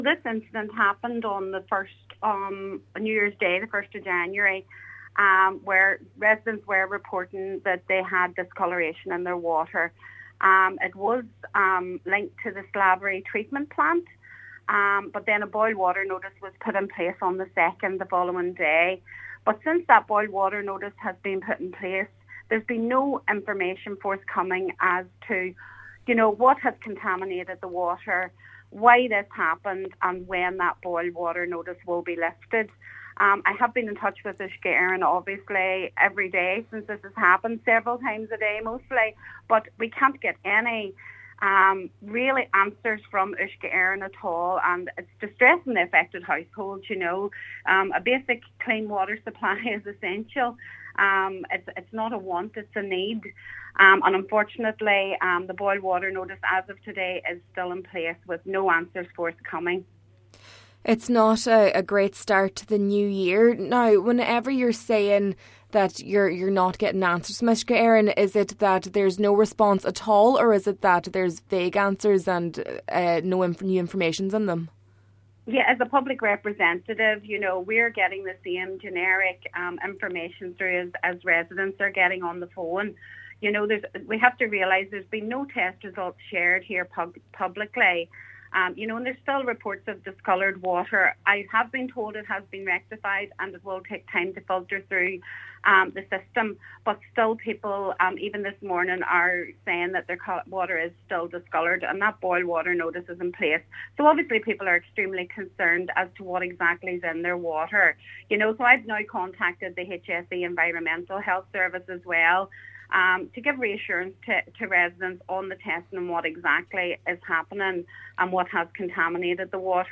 The local councillor says again, that communication with the utility must improve and that she would like to know what containment entered the water source: